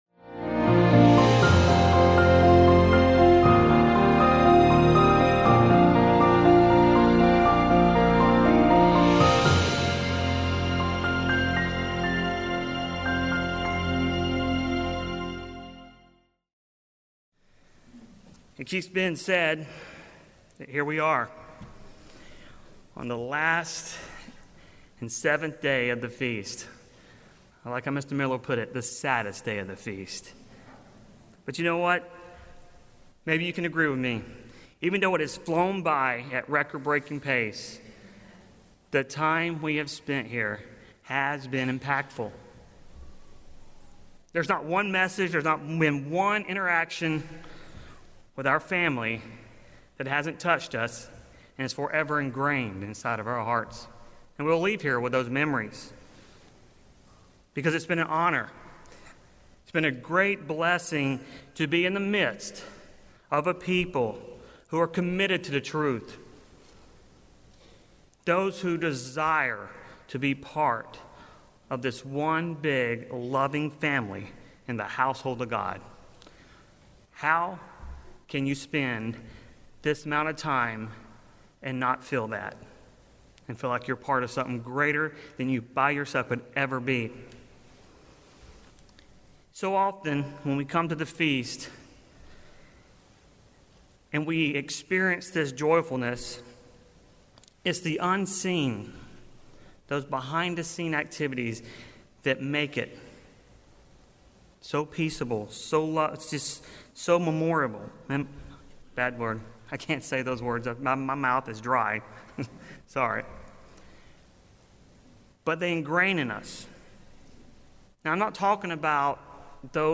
Until that promise comes to pass, we must proclaim this hope for the hopeless and encourage each other to endure to the end. We are here at the Feast of Tabernacles to be reminded of what it will be like when all of our trials and all of our sufferings will be done away.